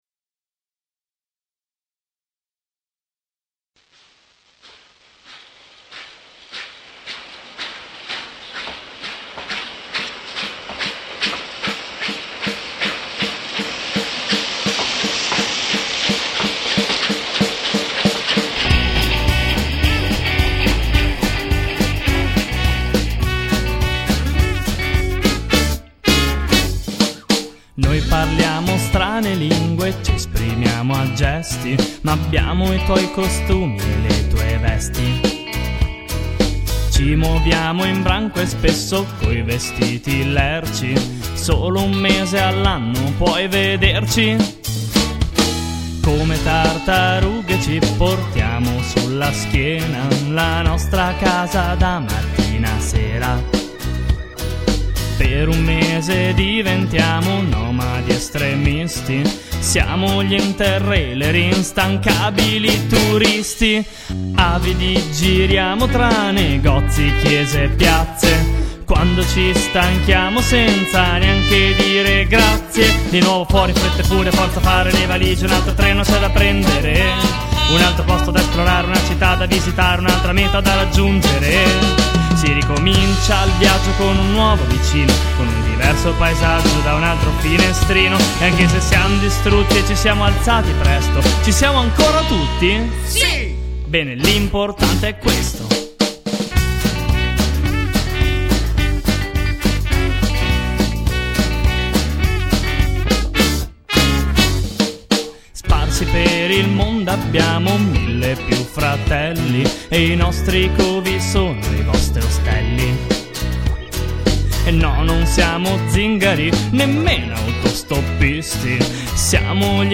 Luogo esecuzioneClosed Studio
GenerePop